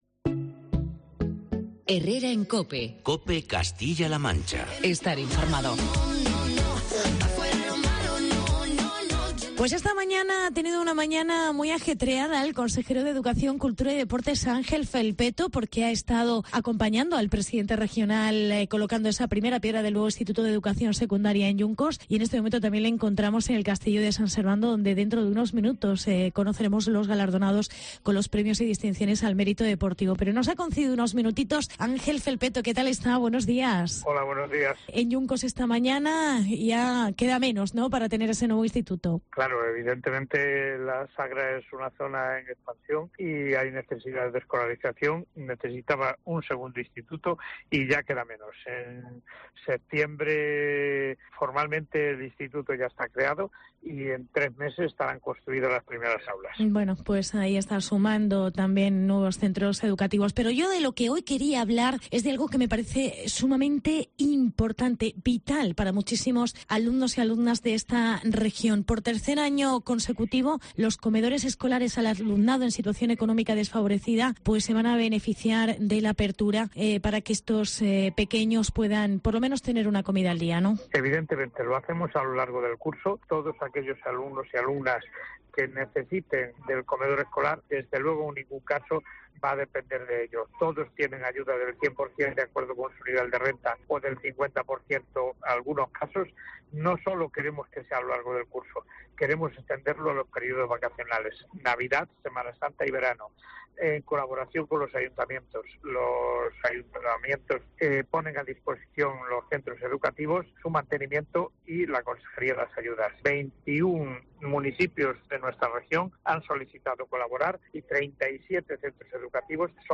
Entrevista con el Consejero de Educación. Ángel Felpeto